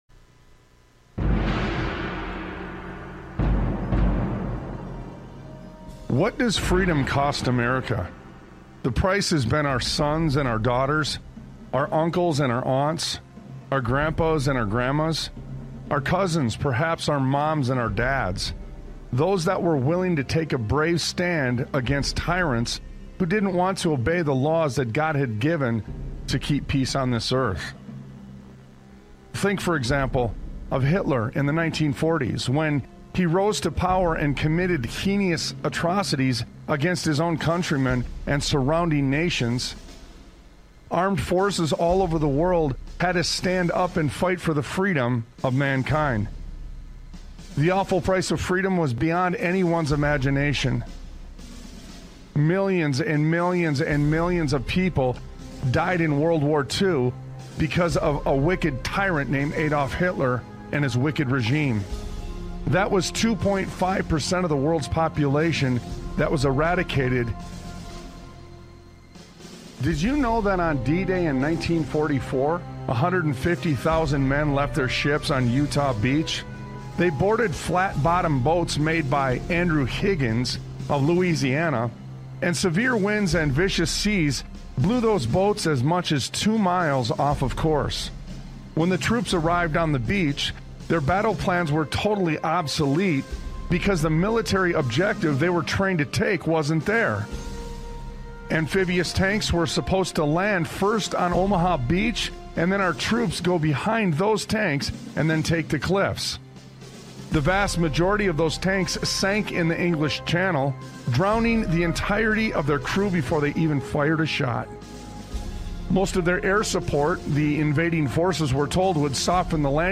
Talk Show Episode, Audio Podcast, Sons of Liberty Radio and What Did They Die For? on , show guests , about What Did They Die For?, categorized as Education,History,Military,News,Politics & Government,Religion,Christianity,Society and Culture,Theory & Conspiracy